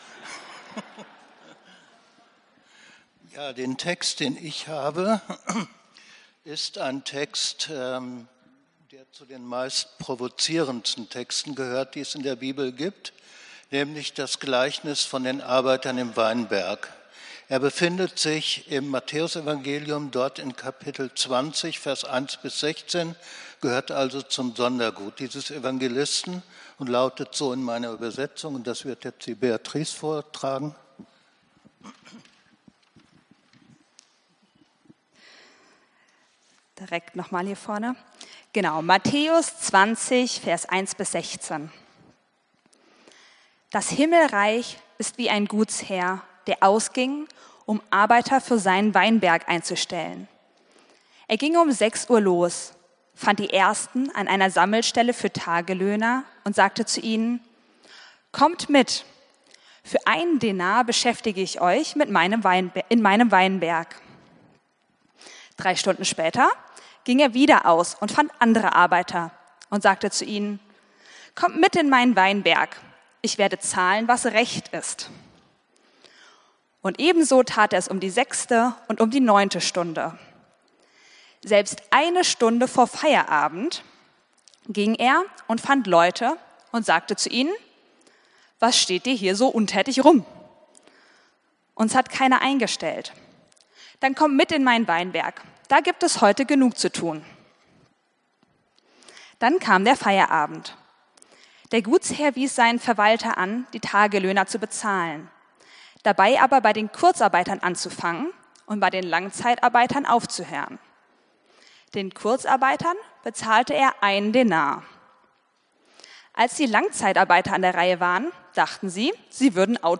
Predigt vom 20.07.2025